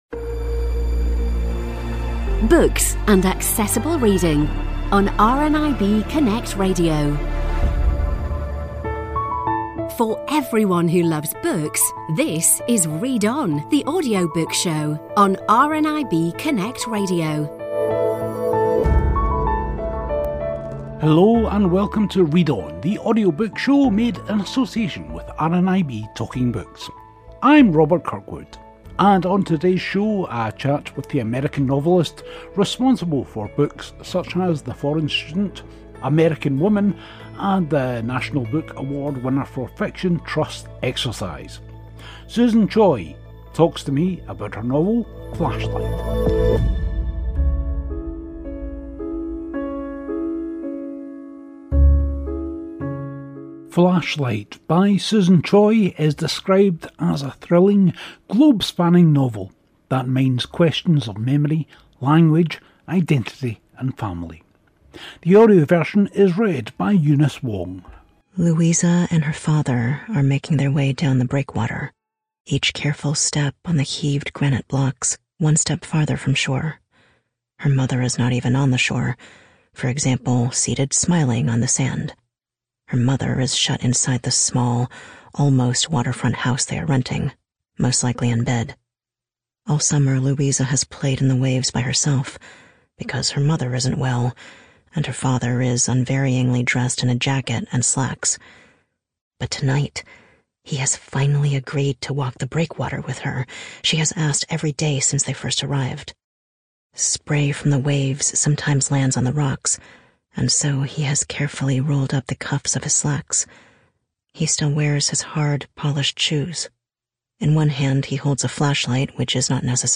has a long chat with Susan Choi on her novel Flashlight.